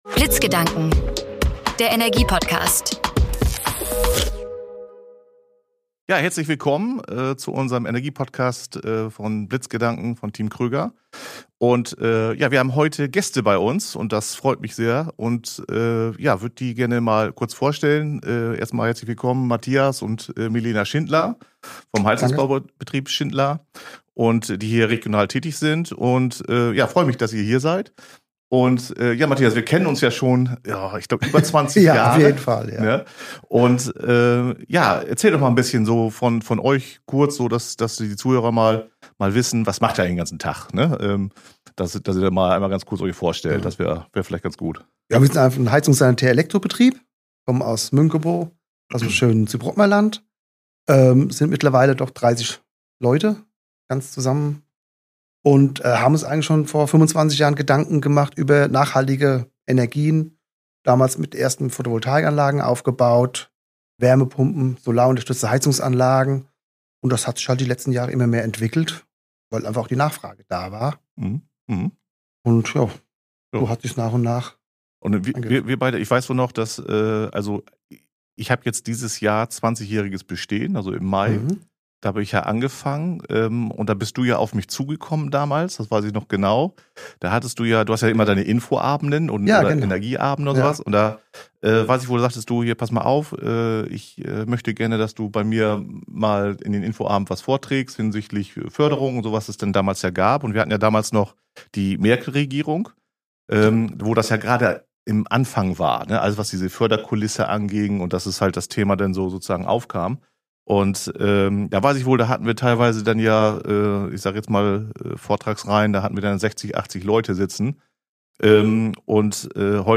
#13 Im Gespräch mit Firma Schindler: Wärmepumpen, Heizungstausch & Hybridlösungen ~ Blitzgedanken Podcast